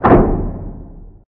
impact-5.ogg